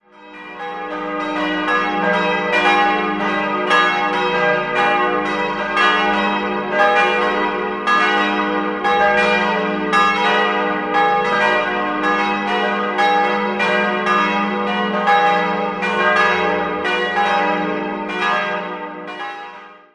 Bei der Pfarrkirche St. Willibald handelt es sich um einen Barockbau, der im Jahr 1747 geweiht wurde.
Idealquartett: dis'-fis'-gis'-h' Alle Glocken wurden 1953 von Friedrich Wilhelm Schilling in Heidelberg gegossen.